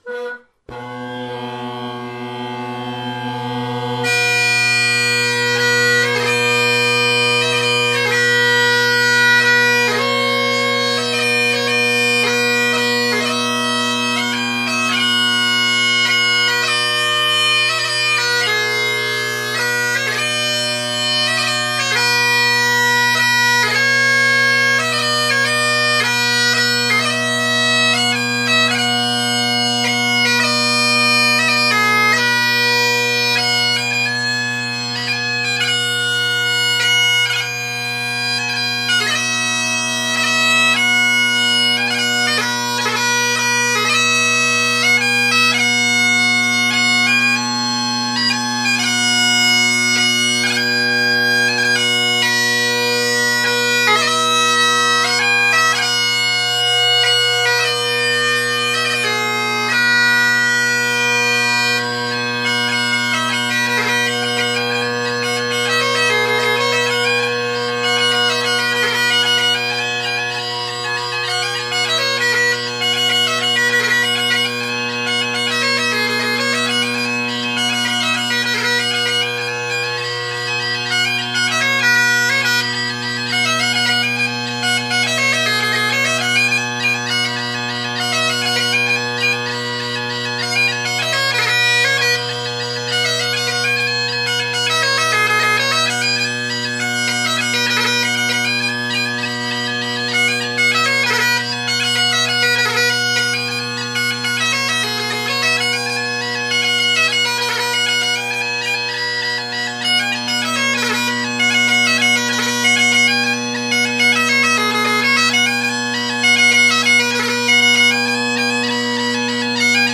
Great Highland Bagpipe Solo
Also, no one else was home so I got to play in a bigger room. The Zoom H2 was placed on top of a free standing bookcase.
The reed in the african blackwood Colin Kyo is new, so it’s crowing a bit on high A.